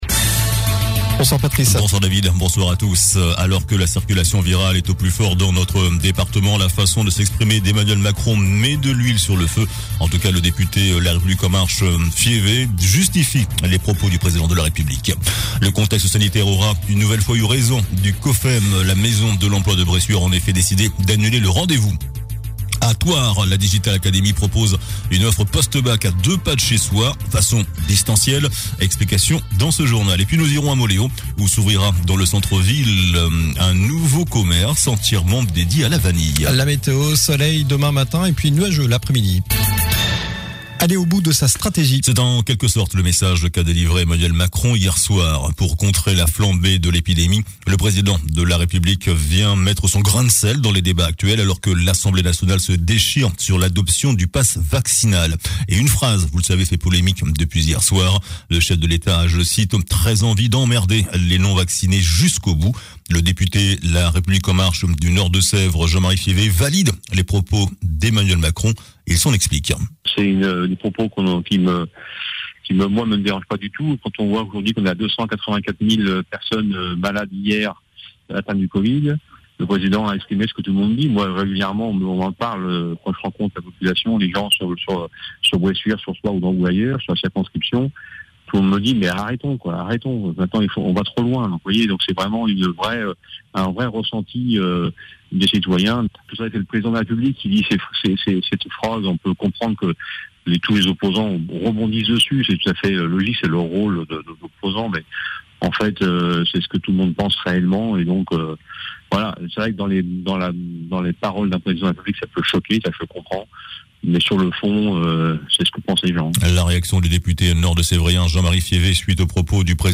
Journal du mercredi 05 janvier (soir)